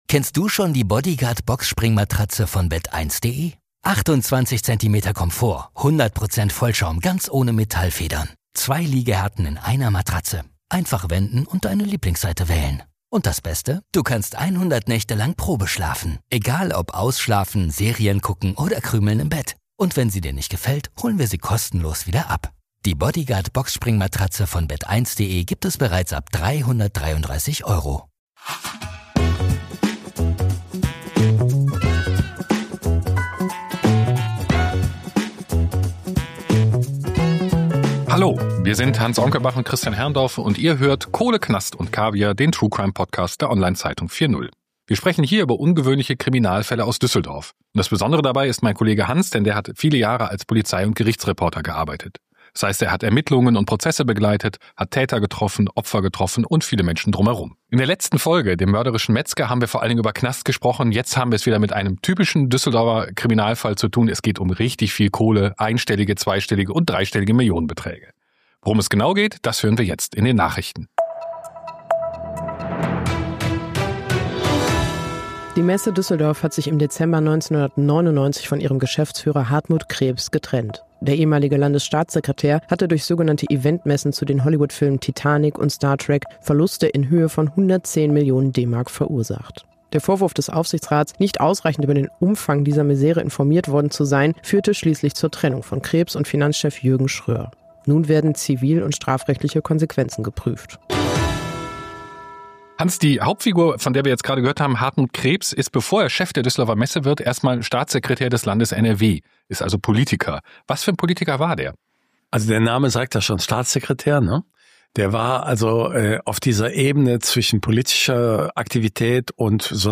in den Folgen begleitet von Zeit- und Augenzeugen